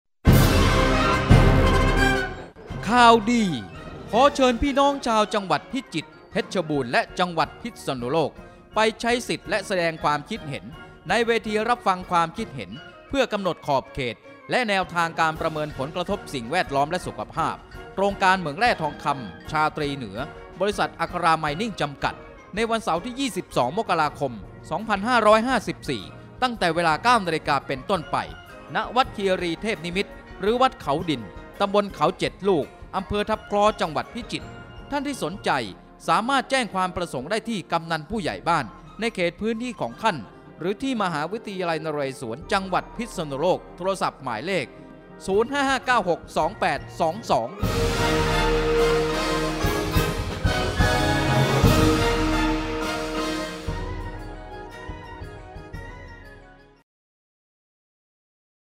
SPOT โฆษณาสำหรับเผยแพร่ตามสถานีวิทยุชุมชนและหมู่บ้าน